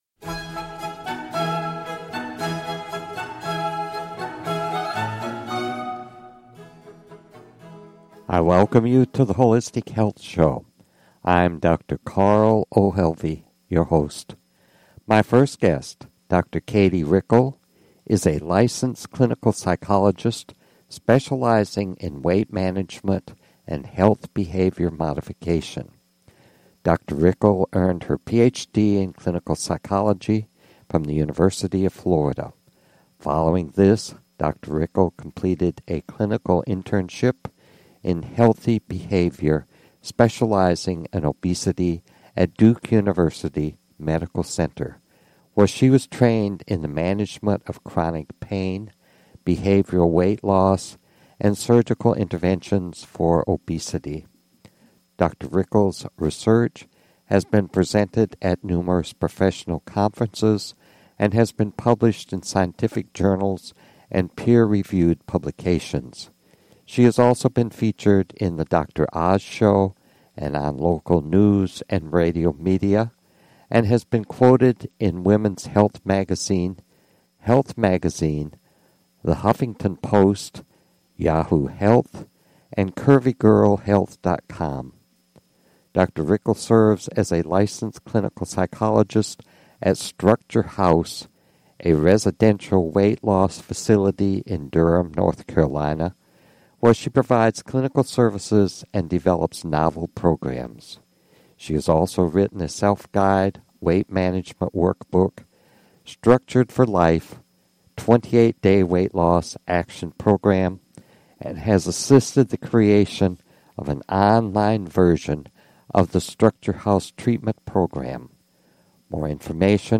Talk Show Episode, Audio Podcast, The_Holistic_Health_Show and Courtesy of BBS Radio on , show guests , about , categorized as